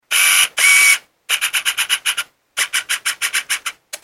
دانلود آهنگ دوربین 8 از افکت صوتی اشیاء
دانلود صدای دوربین 8 از ساعد نیوز با لینک مستقیم و کیفیت بالا
جلوه های صوتی